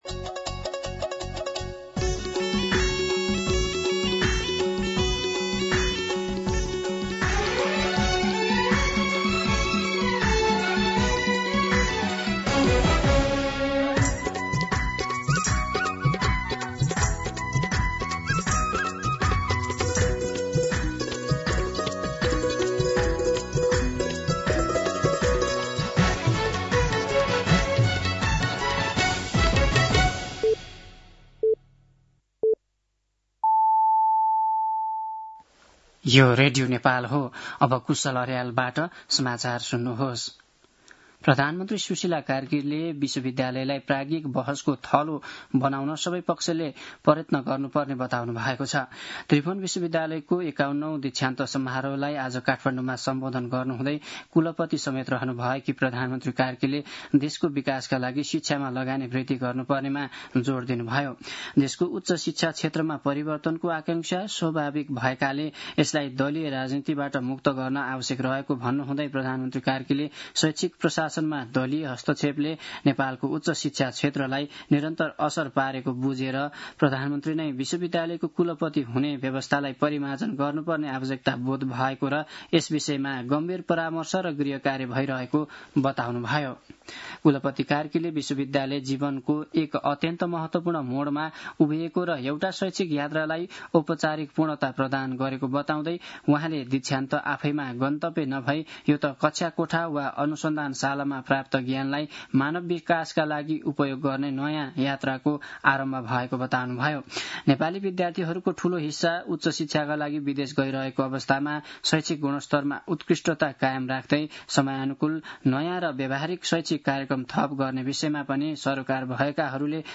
दिउँसो ४ बजेको नेपाली समाचार : १० पुष , २०८२
4-pm-Nepaki-News.mp3